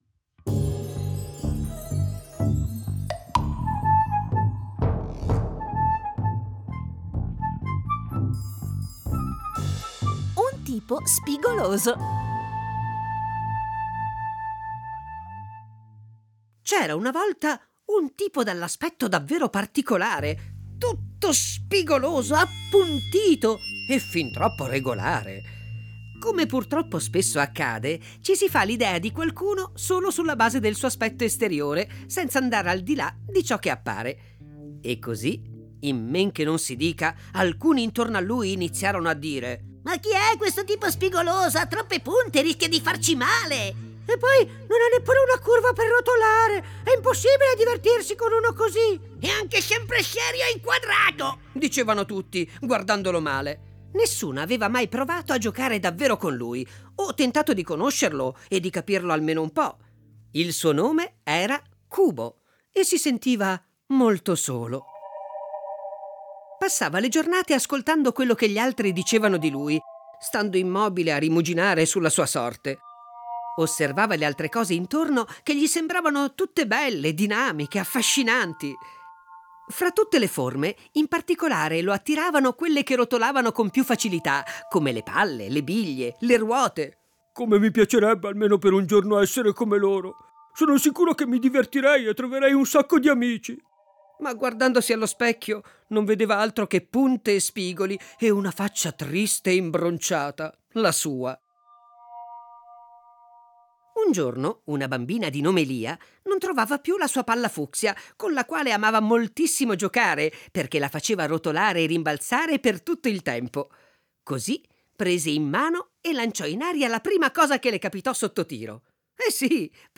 Lettrice
Lettore
Piccole lettrici
Piccoli lettori
Sonorizzazione